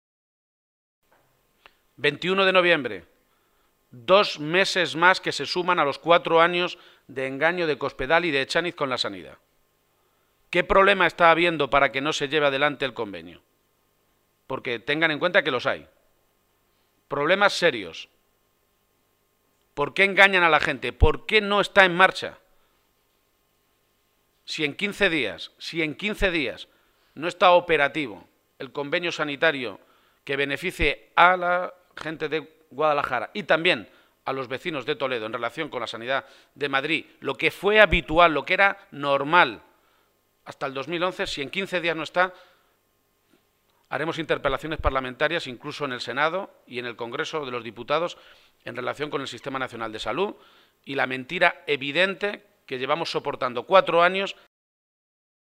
Momento de la rueda de prensa